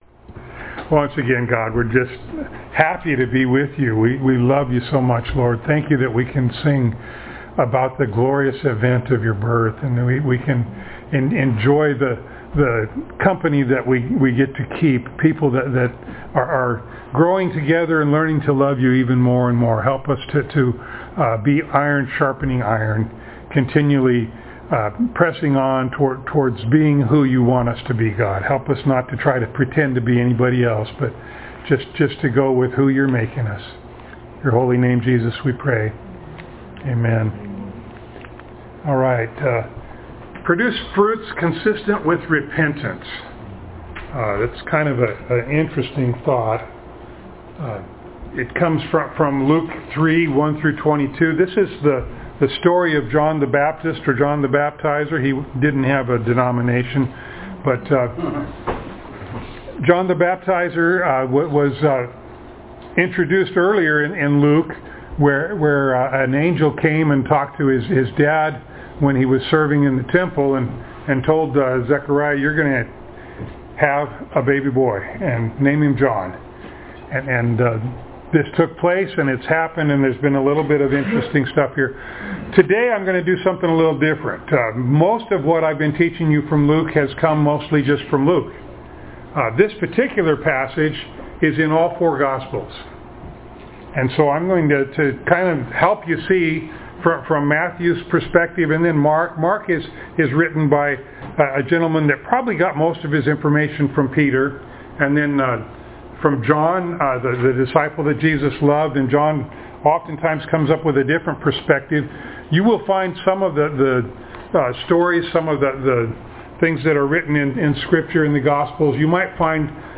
Luke Passage: Luke 3:1-22, Matthew 3:1-17, Mark 1:1-13, John 1:6-8, 19-34 Service Type: Sunday Morning